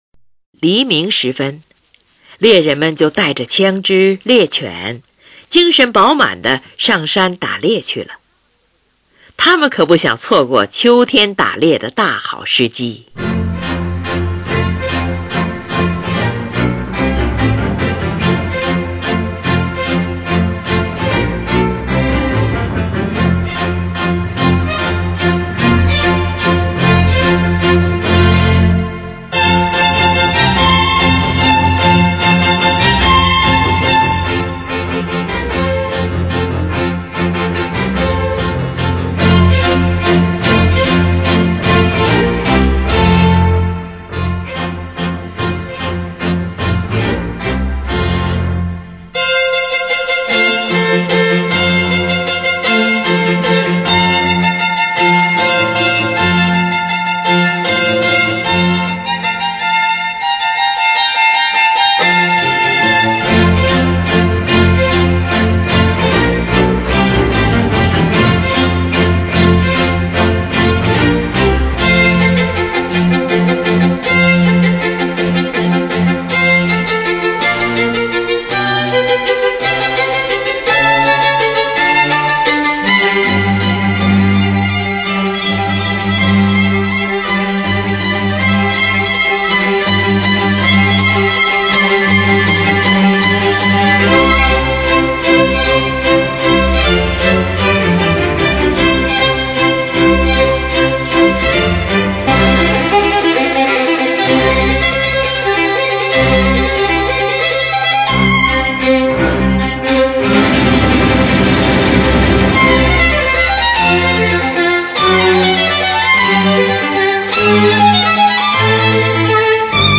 小提琴协奏曲
《秋》描写的是收获季节中，农民们饮酒作乐、庆祝丰收的快活景象，这个乐章欢快而又活泼；
秋天--F大调